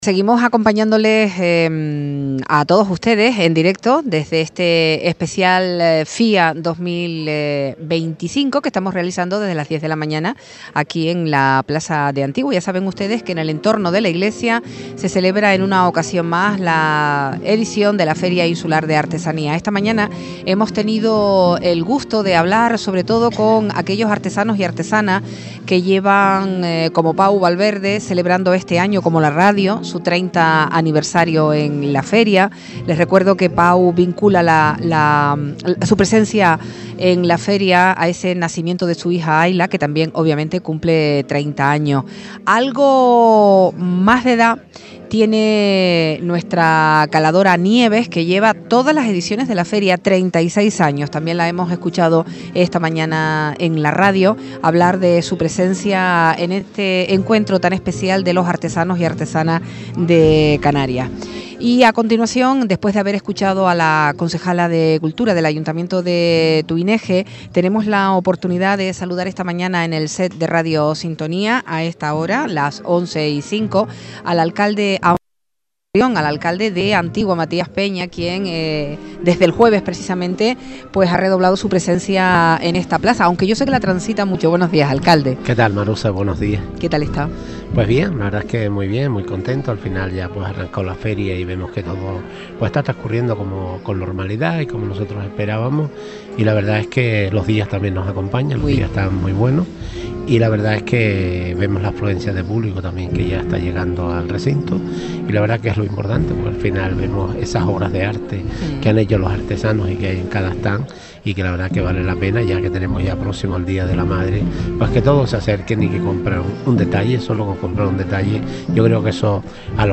Antigua acoge con "orgullo" la edición numero 36 de la Feria Insular de Artesanía, tal y como expresaron hoy, en el especial que Radio Sintonía realizo en directo.
Entrevistas